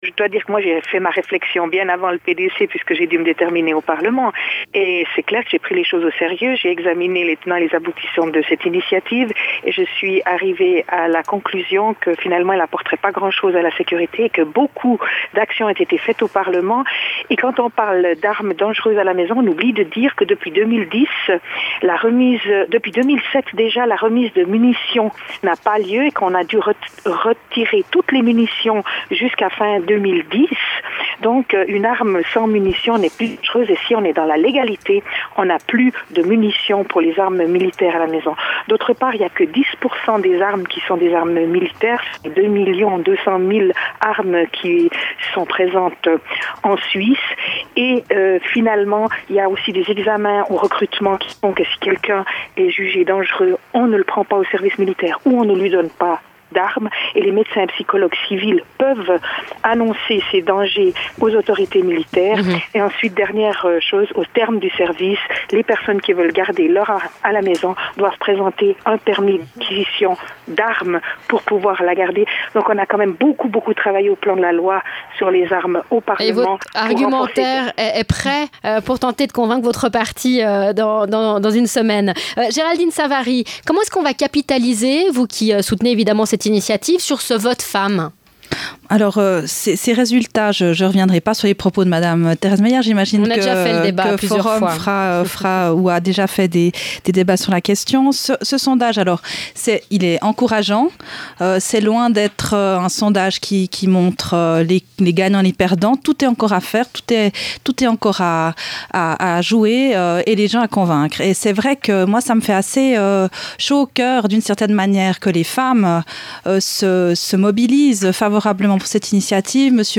RSR 14.01.2011 Débat: la démocrate-chrétienne Thérèse Meyer, Conseillère nationale et la socialiste Géraldine Savary
Thérèse Meyer, Conseillère nationale (Chambre basse) démocrate chrétienne et Géraldine Savary, Conseillère aux Etats (Chambre haute) socialiste